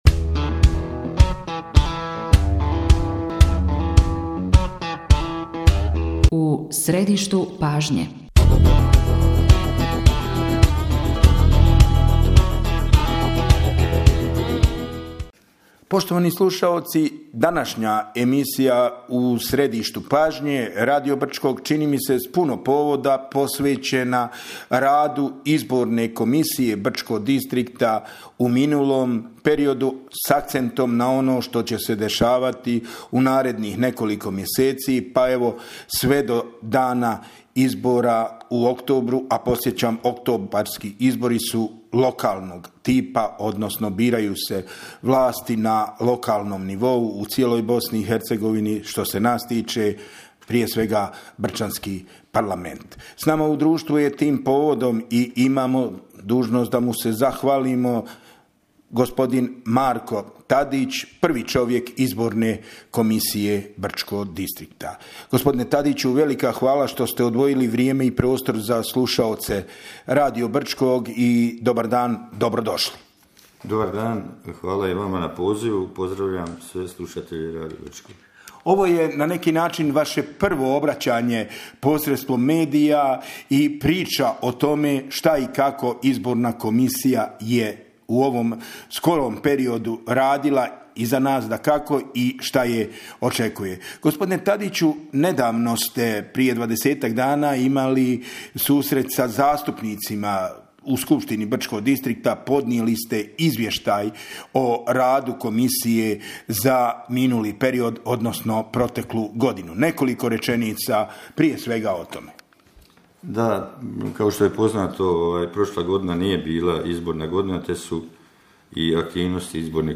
Sa predsjednikom Izbornog povjerenstva Brčko distrikta BiH Markom Tadićem razgovarali smo o predstojećem izbornom ciklusu.